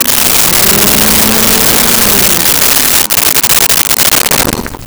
Vacuum Cleaner On And Off
Vacuum Cleaner On and Off.wav